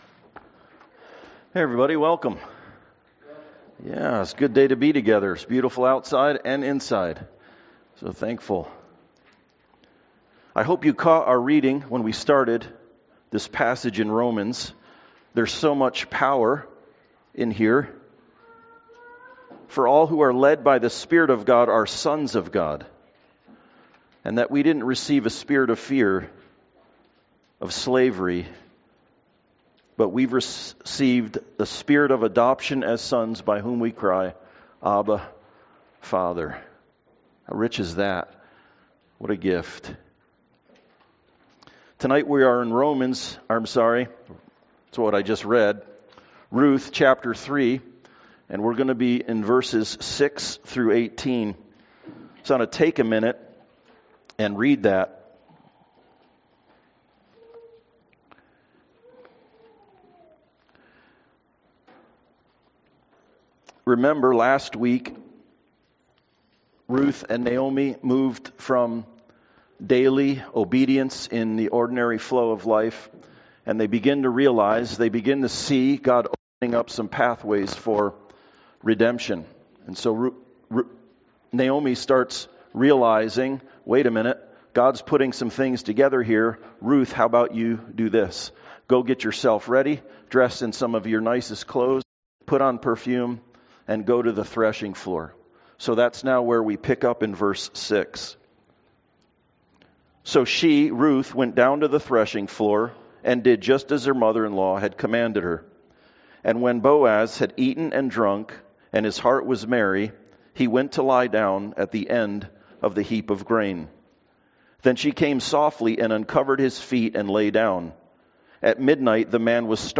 Ruth 3:6-18 Service Type: Sunday Service Sure victory